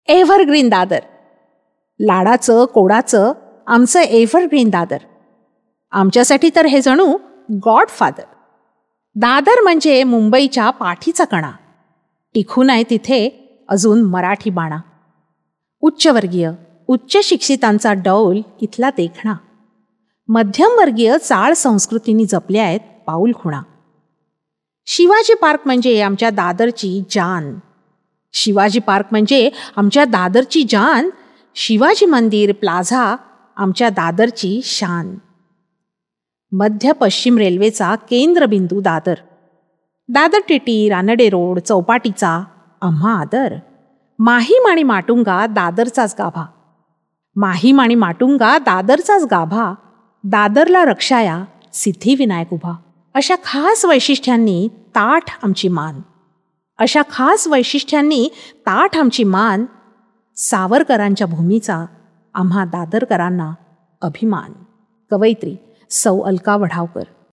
Dadar-poem-with-pause.mp3